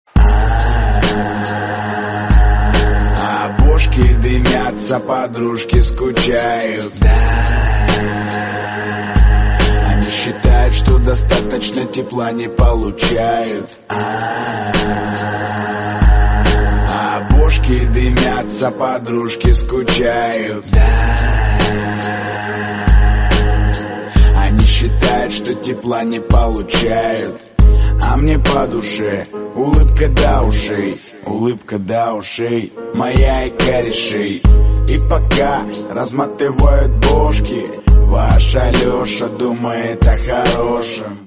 Aaaaaaah sound effects free download